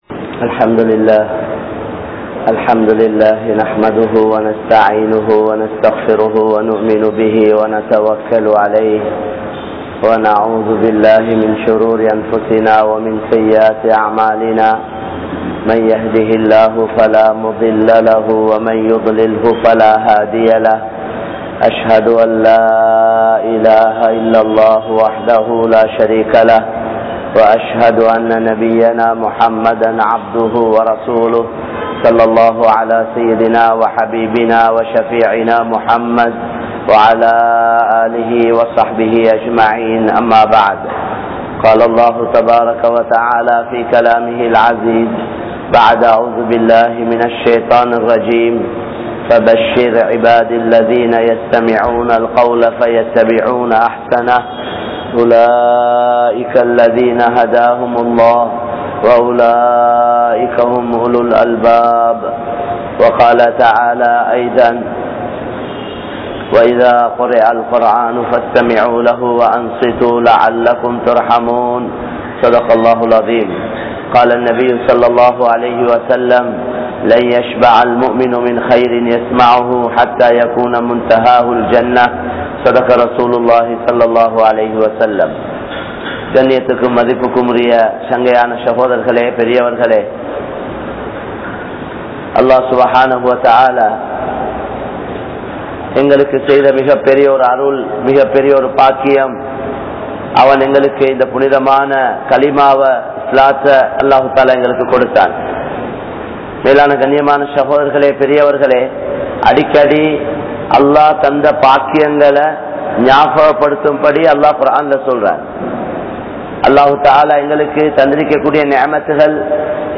Ahlaaq (அஹ்லாக்) | Audio Bayans | All Ceylon Muslim Youth Community | Addalaichenai